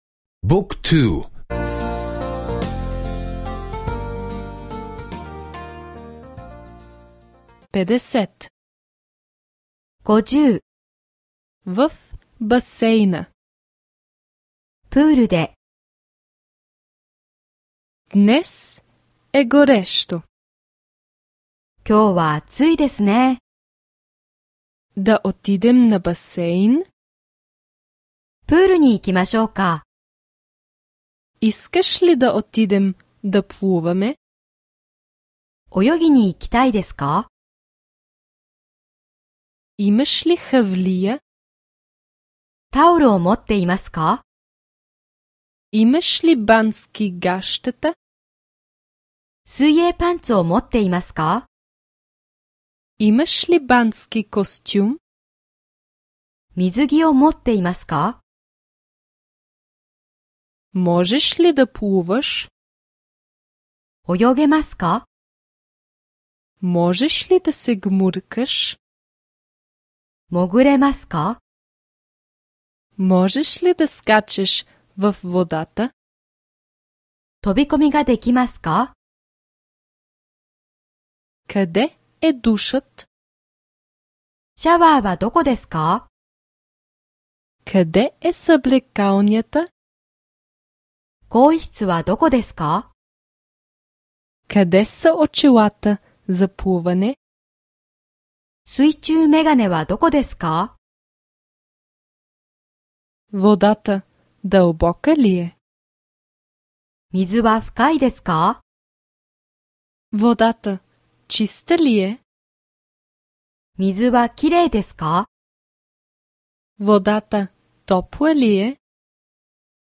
Аудиокурс по японски език (безплатно сваляне)